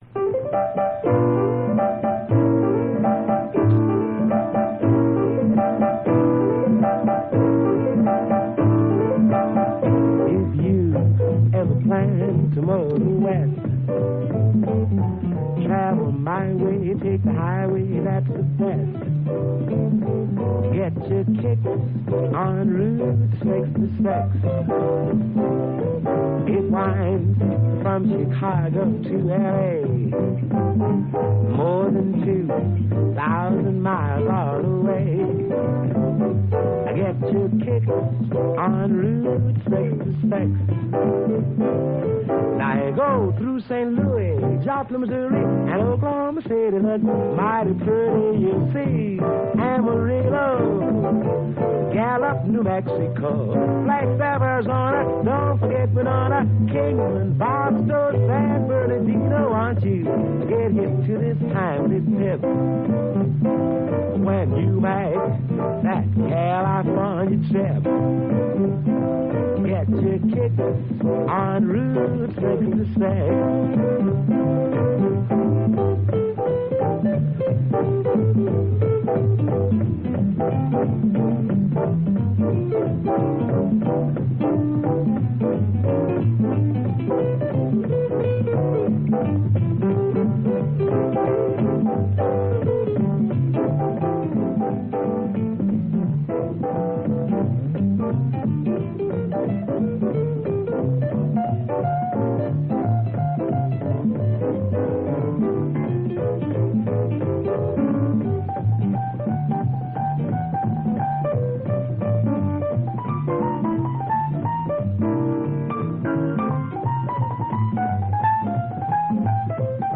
Jazz, Traditional Pop, Vocal